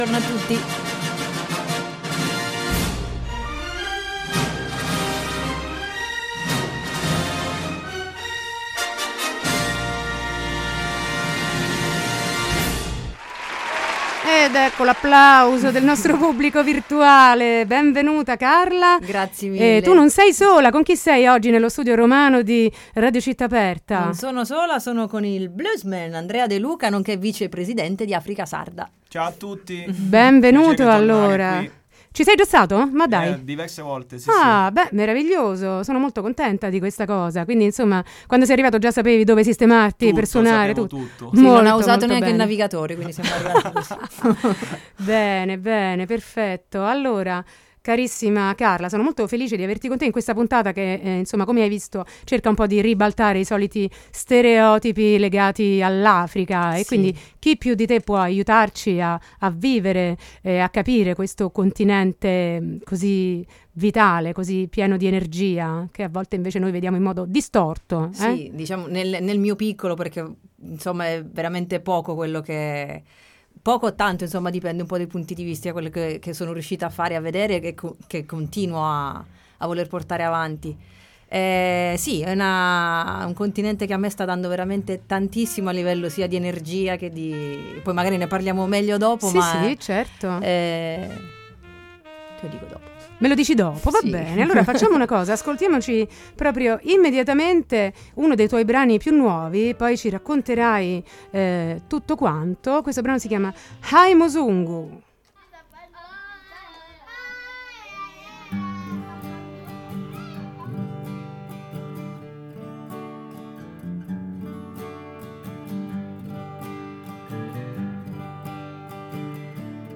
Intervista e minilive